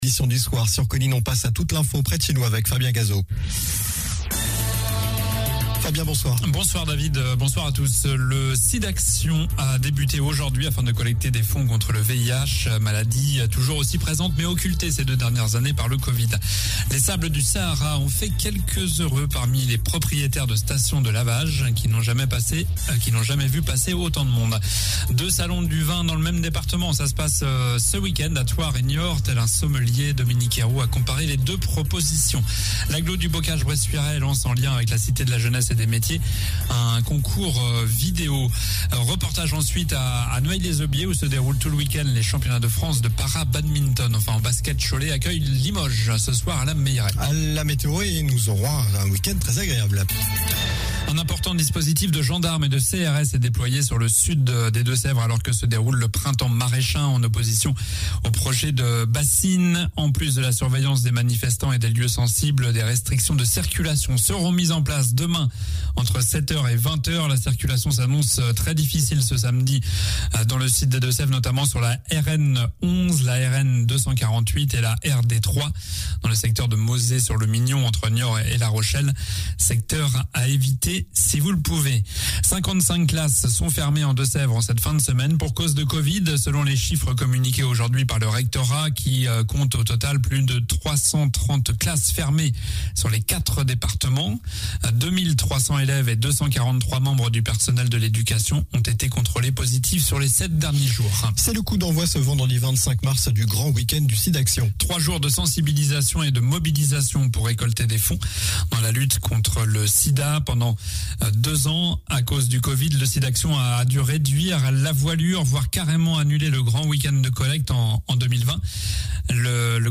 Journal du vendredi 25 mars (soir)